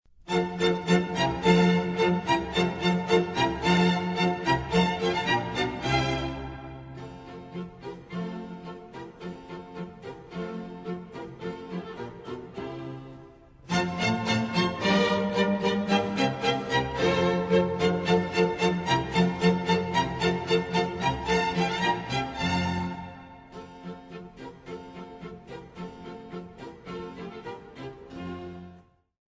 Antonio Vivaldi (1678-1741), musicista veneziano, compone Le quattro stagioni, concerti per violino, archi e cembalo.
La musica è luminosa, piena di colore; la melodia viene eseguita su diversi piani sonori: una volta più forte , un'altra, più piano .